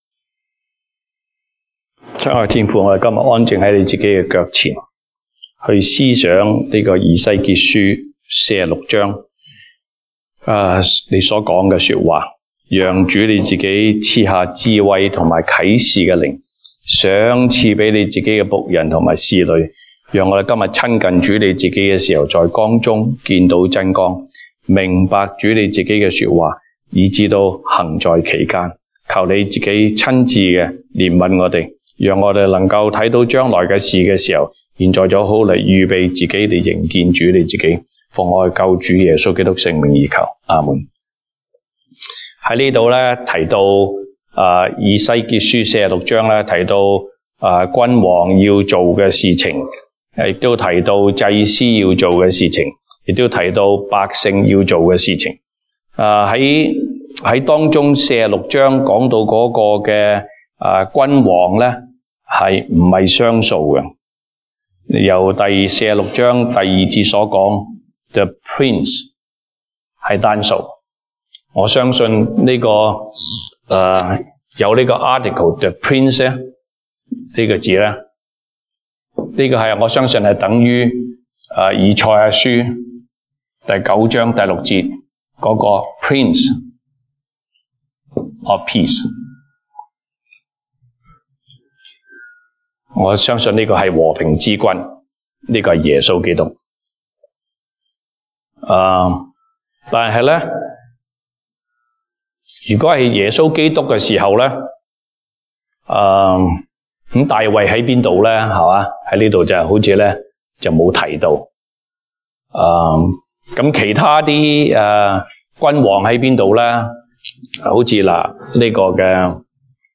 東北堂證道 (粵語) North Side: 君王和祭司與子民的分別
Passage: 以西結書 Ezekiel 46:1-24 Service Type: 東北堂證道 (粵語) North Side (First Church)